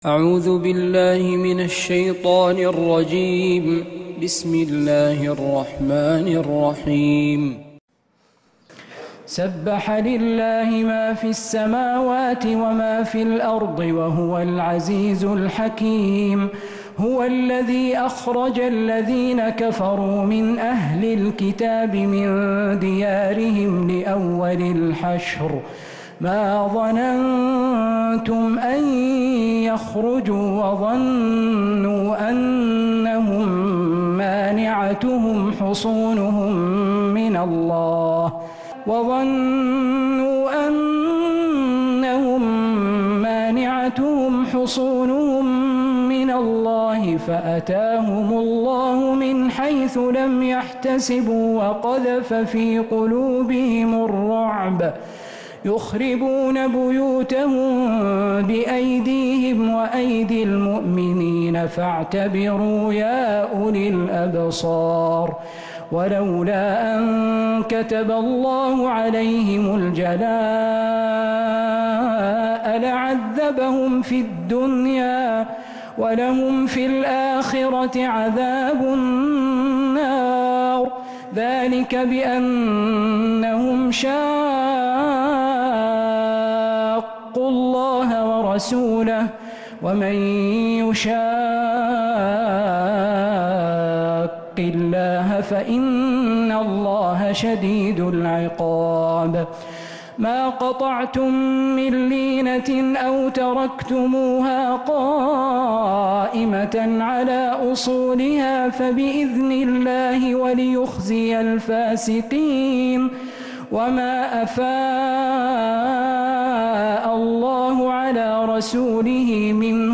سورة الحشرمن تراويح الحرم النبوي